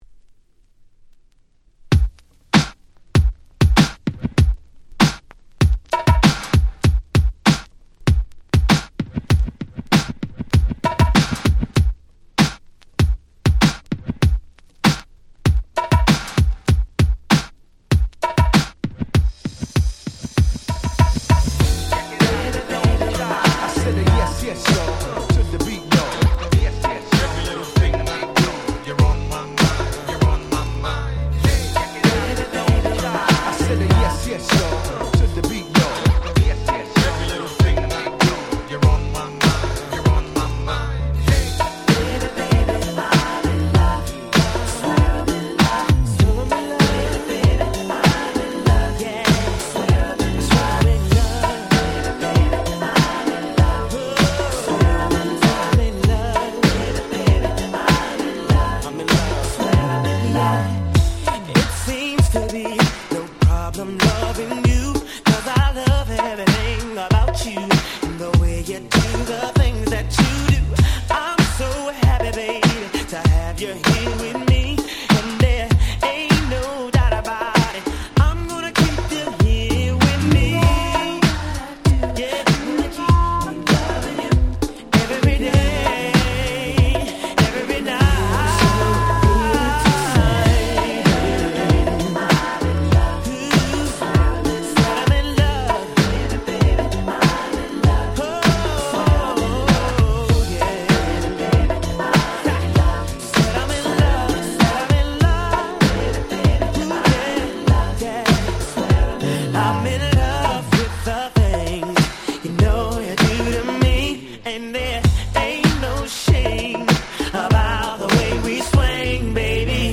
しかもこの盤オンリーとなる非常に使い易いEditに！！